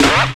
112 SNARE 2.wav